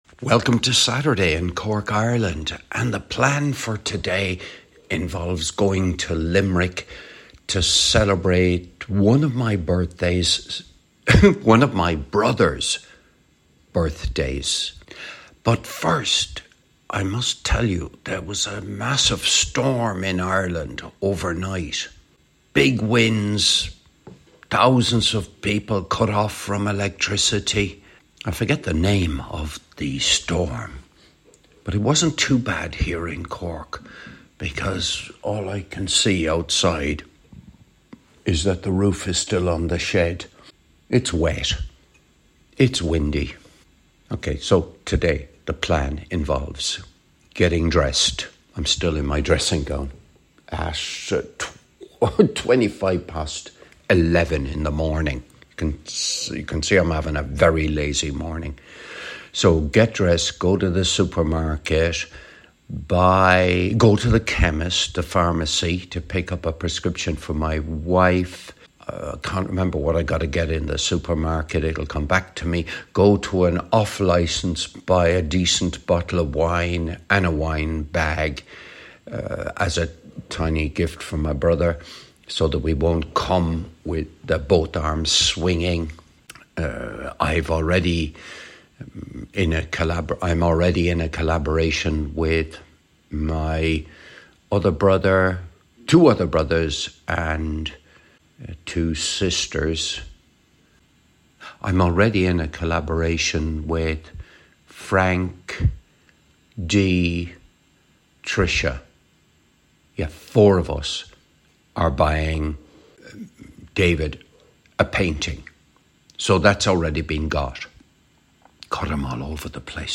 This was recorded in my kitchen on Saturday 23rd November 2024